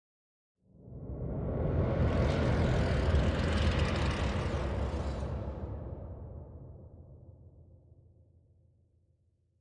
Minecraft Version Minecraft Version 1.21.5 Latest Release | Latest Snapshot 1.21.5 / assets / minecraft / sounds / ambient / nether / crimson_forest / mood2.ogg Compare With Compare With Latest Release | Latest Snapshot